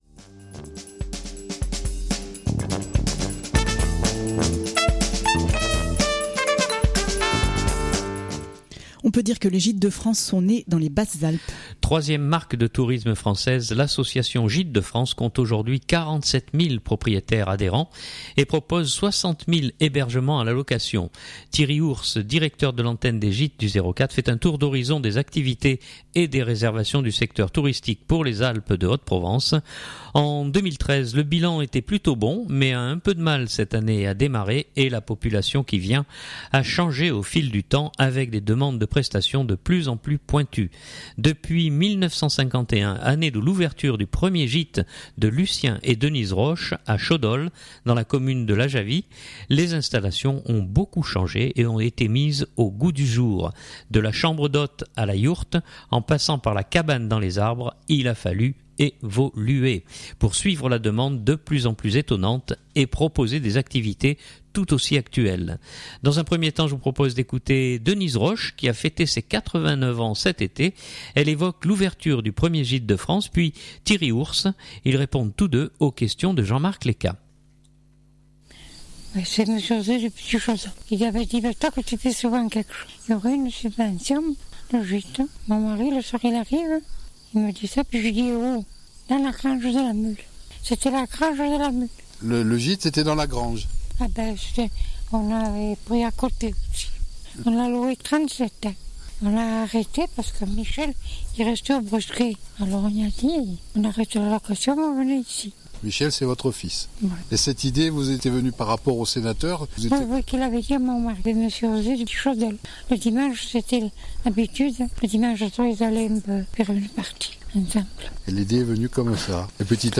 Durée du reportage